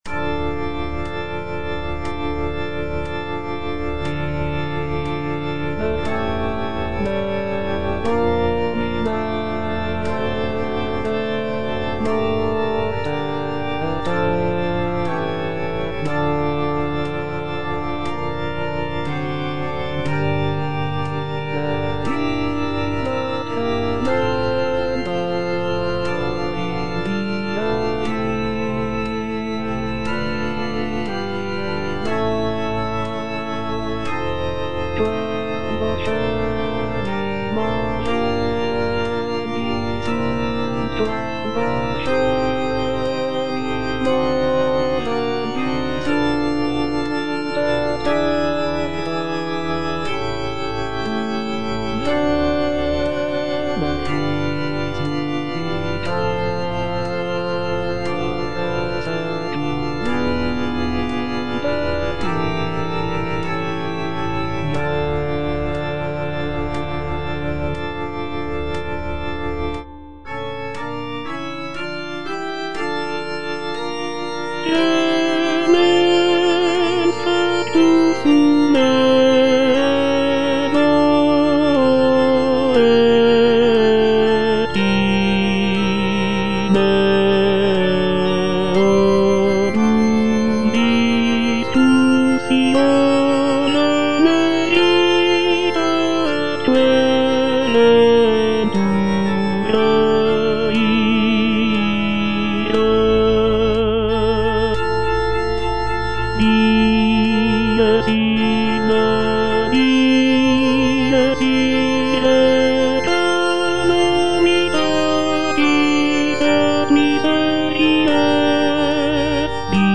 G. FAURÉ - REQUIEM OP.48 (VERSION WITH A SMALLER ORCHESTRA) Libera me (tenor I) (Voice with metronome) Ads stop: